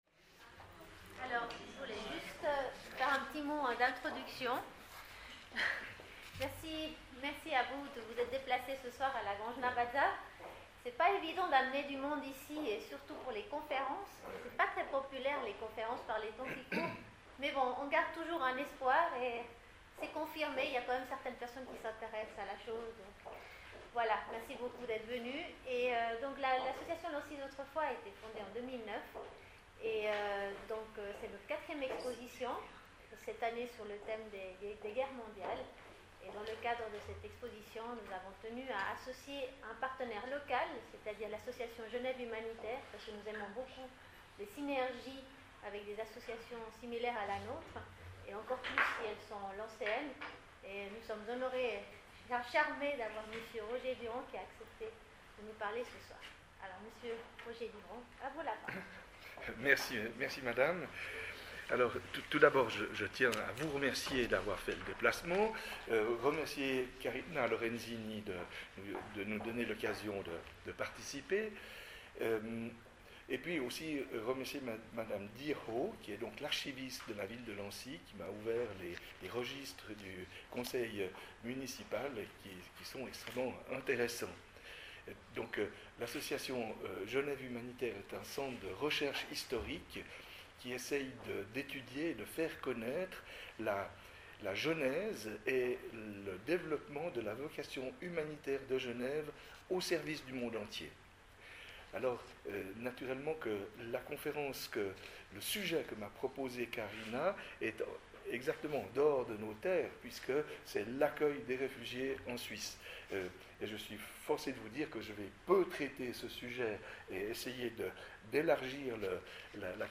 Ecoutez la conf�rence Enregistrement r�alis� le mardi 29 septembre 2015 � la Grange Navazza